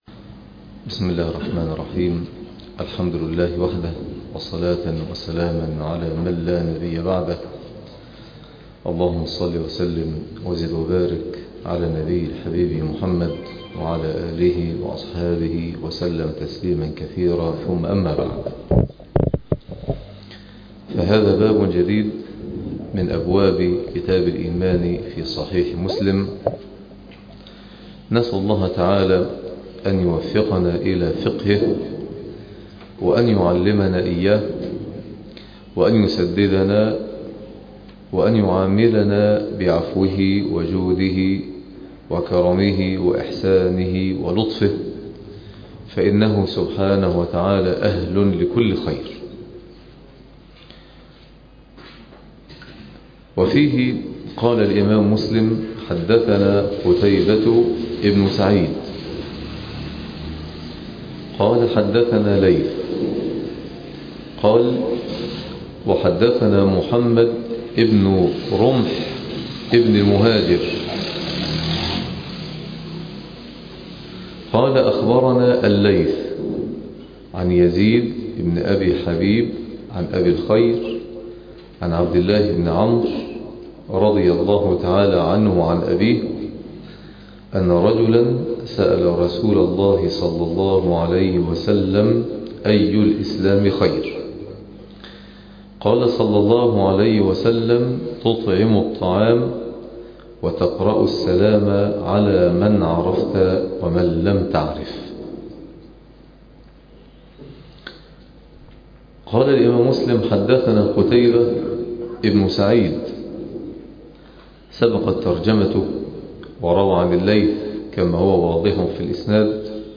عنوان المادة الدرس ( 40)شرح كتاب الإيمان صحيح مسلم تاريخ التحميل الأحد 25 ديسمبر 2022 مـ حجم المادة 25.08 ميجا بايت عدد الزيارات 226 زيارة عدد مرات الحفظ 102 مرة إستماع المادة حفظ المادة اضف تعليقك أرسل لصديق